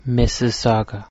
1. ^ /ˌmɪsɪˈsɔːɡə/
MISS-iss-AW-gə
Mississauga.ogg.mp3